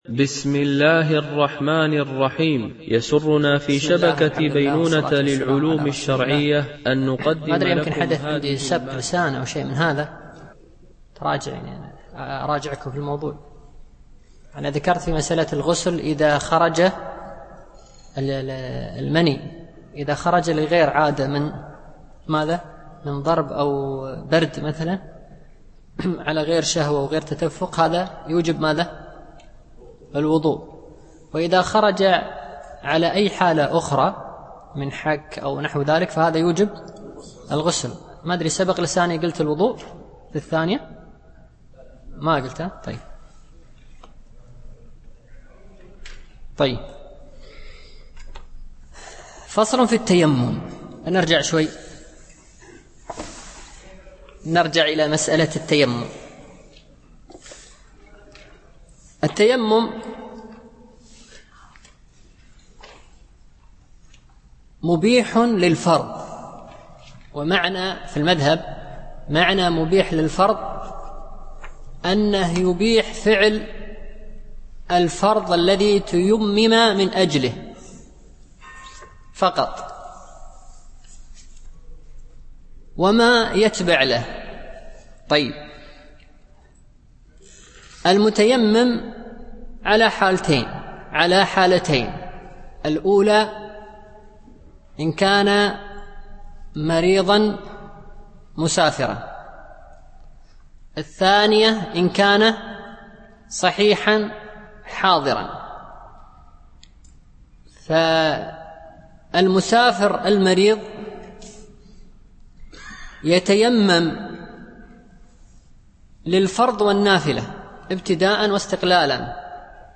شرح الفقه المالكي ( المستوى الأول - متن الأخضري ) - الدرس 4